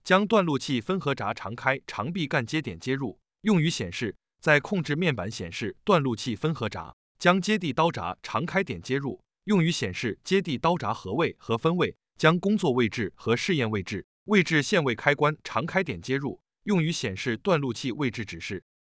真空断路器3.mp3